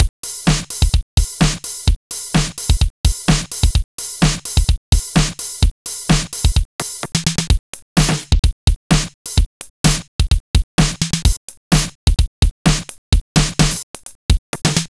Session 11 - Mixed Beat 01.wav